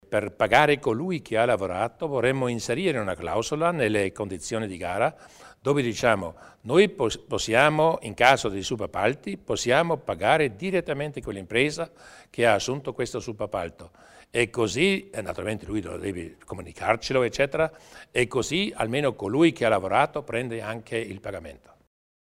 Il Presidente Durnwalder illustra i dettagli per garantire il pagamento dei subappalti